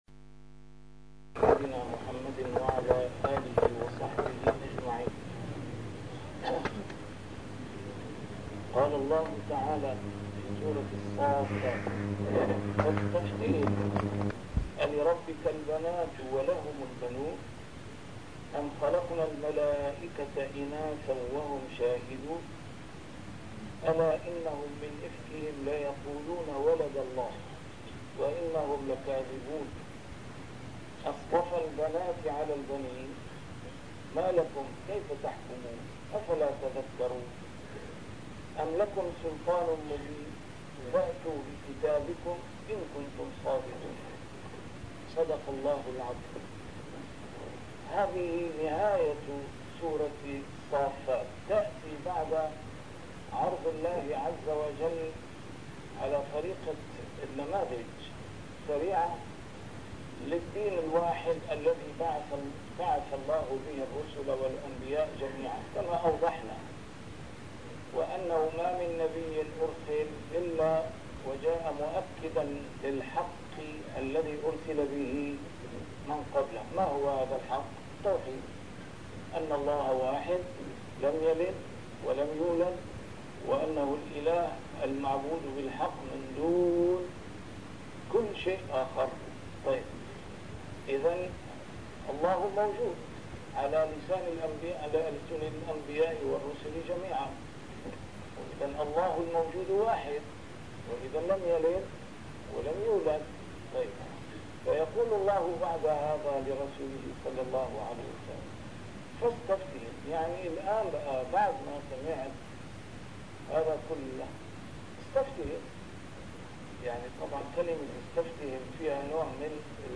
A MARTYR SCHOLAR: IMAM MUHAMMAD SAEED RAMADAN AL-BOUTI - الدروس العلمية - تفسير القرآن الكريم - تسجيل قديم - الدرس 459: الصافات 149-158
تفسير القرآن الكريم - تسجيل قديم - A MARTYR SCHOLAR: IMAM MUHAMMAD SAEED RAMADAN AL-BOUTI - الدروس العلمية - علوم القرآن الكريم - الدرس 459: الصافات 149-158